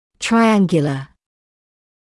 [traɪ’æŋgjələ][трай’энгйэлэ]треугольный